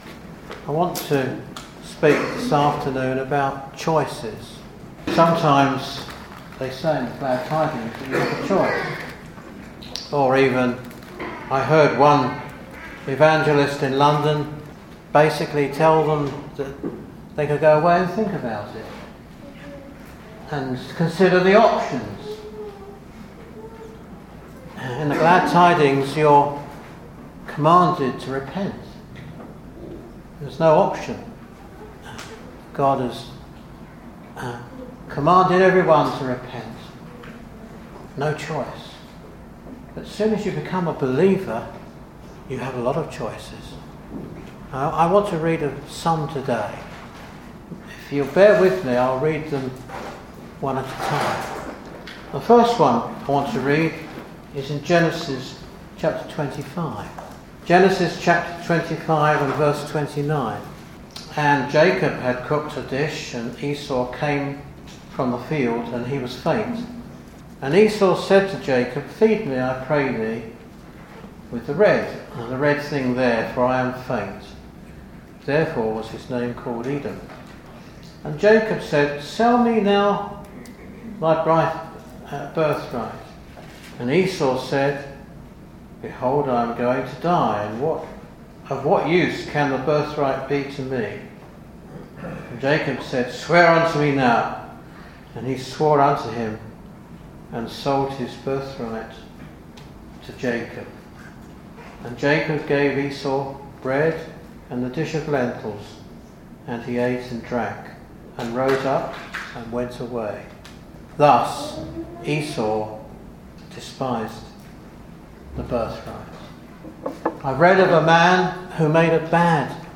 In this address, three powerful examples of choice are explored: A Bad Choice (Esau selling his birthright), No Choice (the men of Jabesh-Gilead facing humiliation), and A Good Choice (Ruth’s faithful commitment and Joshua’s resolve to serve Jehovah). Drawing from Genesis, 1 Samuel, Ruth, Hebrews, and Joshua , this message challenges listeners to reflect on the spiritual impact of their decisions and the value of choosing God’s way.